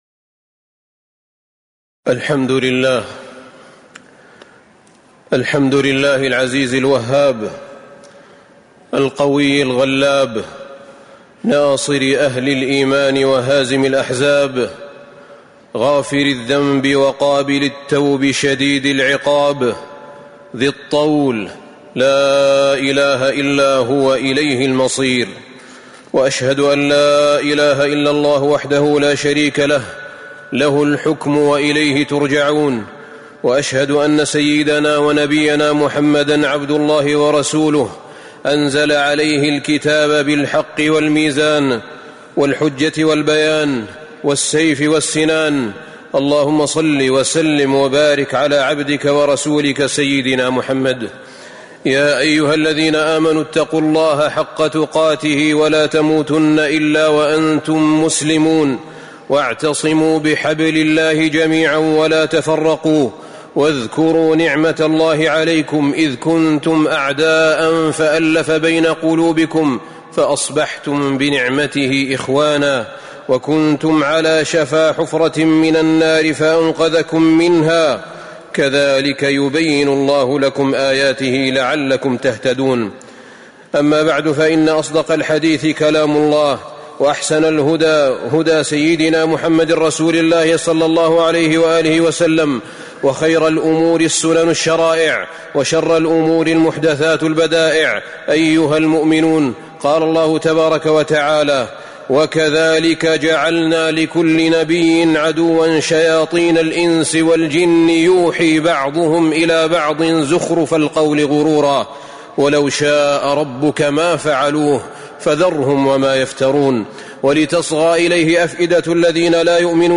تاريخ النشر ٢٨ ربيع الأول ١٤٤٥ هـ المكان: المسجد النبوي الشيخ: فضيلة الشيخ أحمد بن طالب بن حميد فضيلة الشيخ أحمد بن طالب بن حميد وما النصر إلا من عند الله العزيز الحكيم